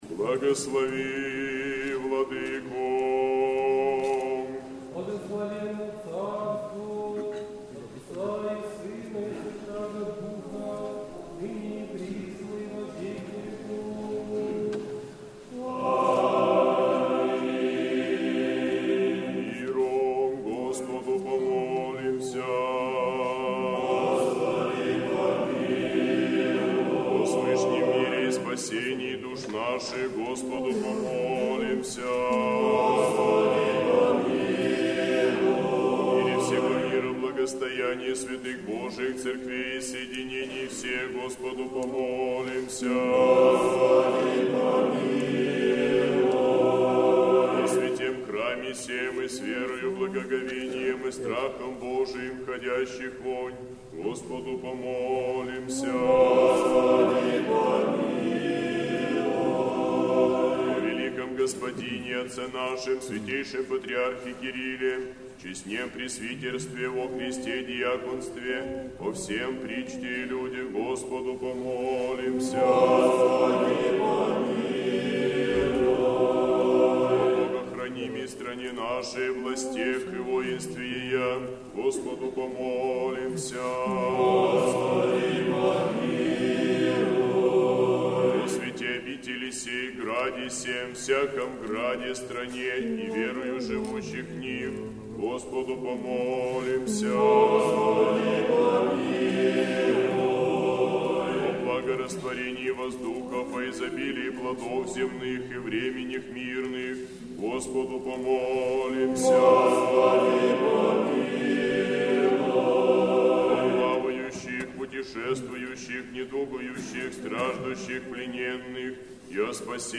Божественная литургия. Хор Сретенской Духовной семинарии.
Божественная литургия в Неделю 25-ю по Пятидесятнице в Сретенском монастыре